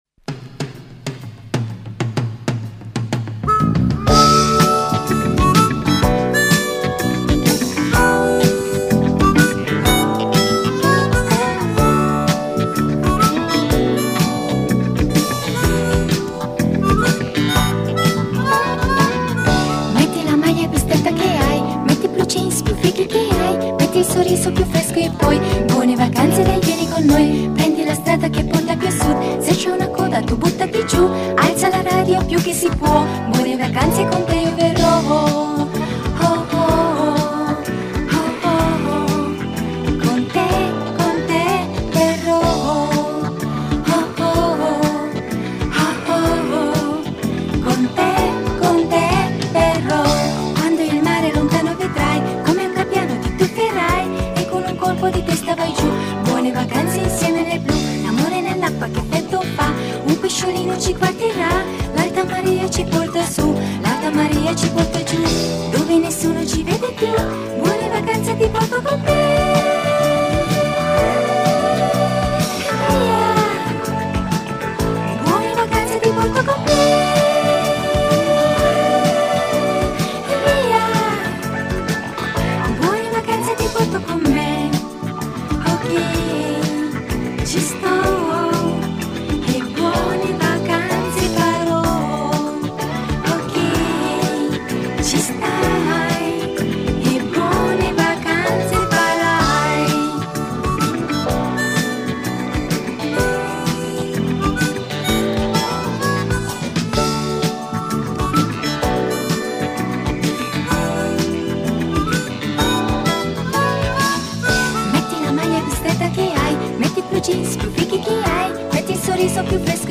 موسیقی ایتالیایی
سبک دیسکو Disco Music